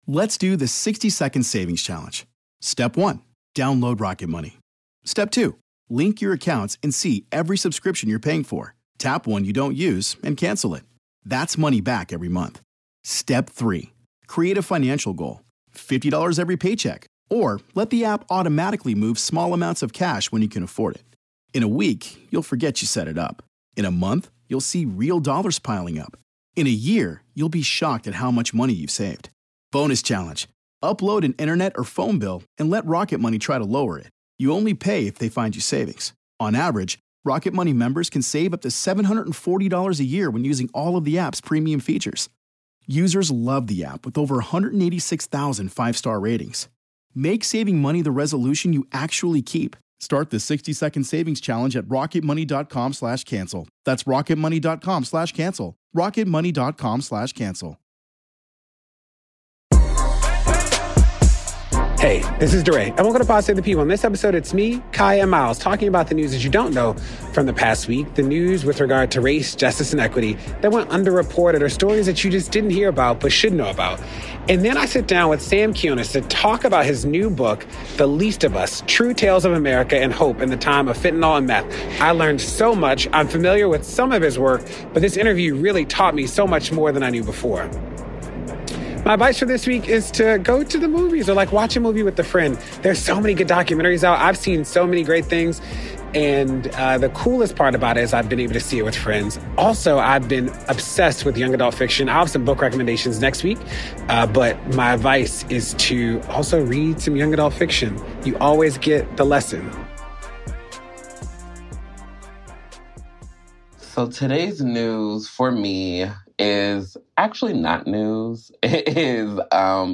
DeRay interviews Sam Quinones about his newest book The Least of Us: True Tales of America and Hope in the Time of Fentanyl and Meth.